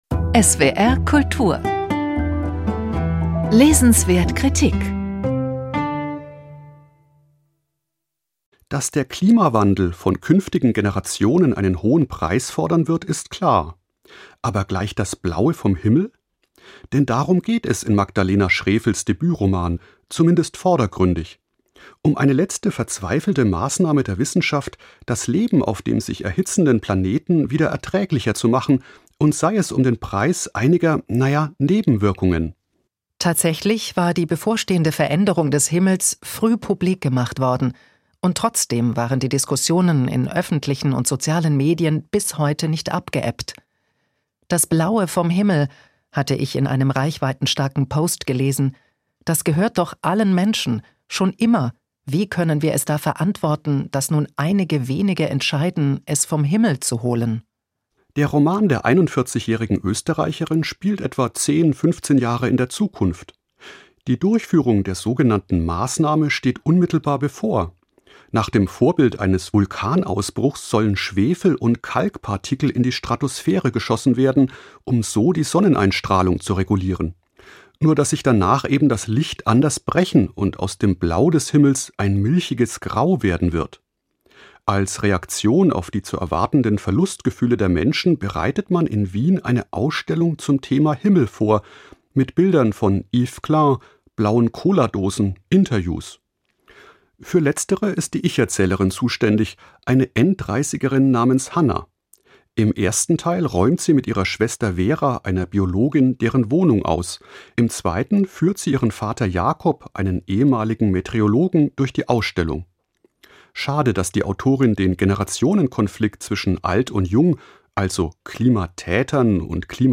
Magdalena Schrefel – Das Blaue vom Himmel | Buchkritik